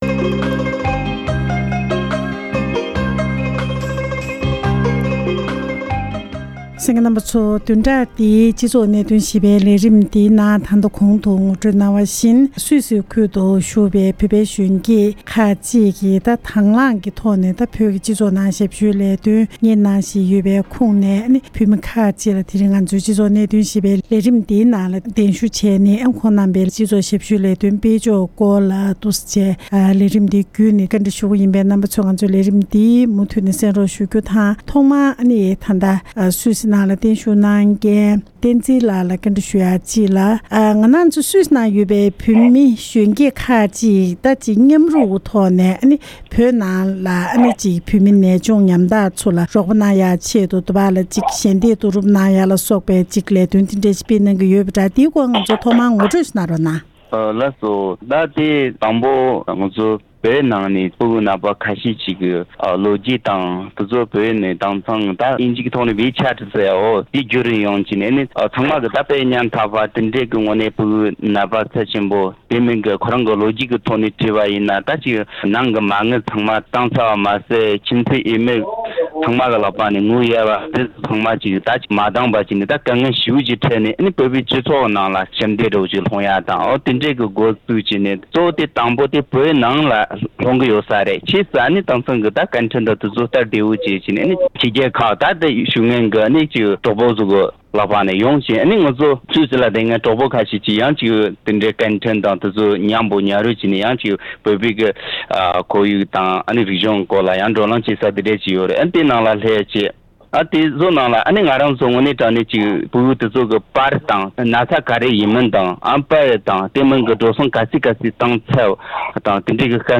འབྲེལ་ཡོད་མི་སྣར་ཞལ་པར་བརྒྱུད་བཀའ་བཅར་འདྲི་ཞུས་པར་གསན་རོགས་ཞུ༎